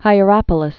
(hī-ə-răpə-lĭs)